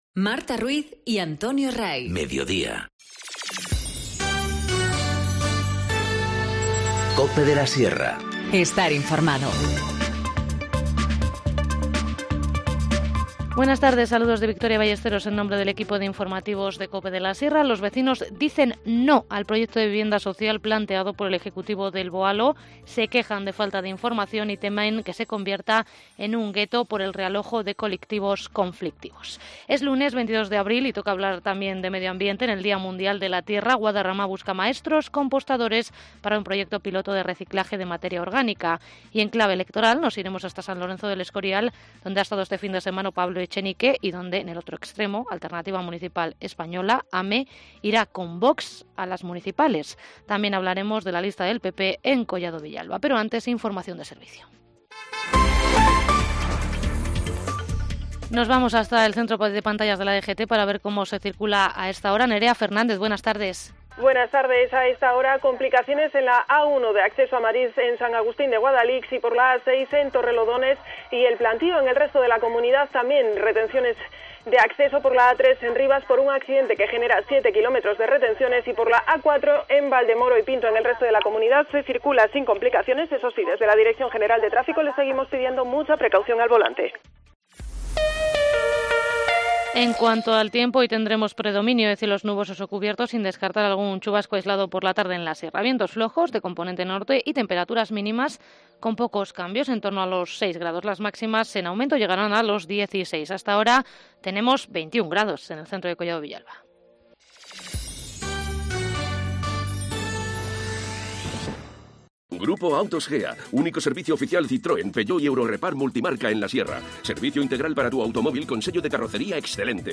Informativo Mediodía 22 abril 14:20h